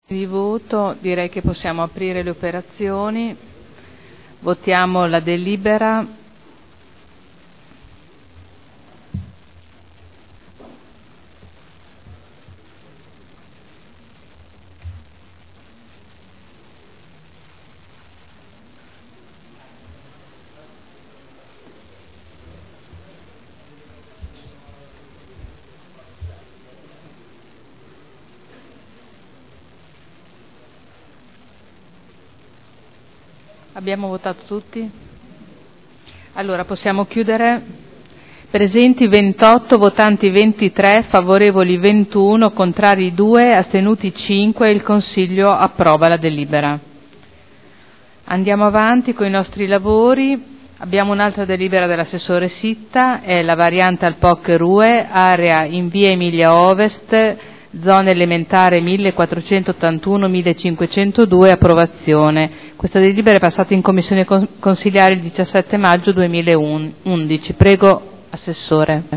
Presidente — Sito Audio Consiglio Comunale
Seduta del 30/05/2011. Mette ai voti proposta di deliberazione: Variante al POC e al RUE relativamente a pubblici esercizi e merceologie ingombranti, aggiornamento della disciplina degli immobili con codici ISTAR-ATECO 2002 – Approvazione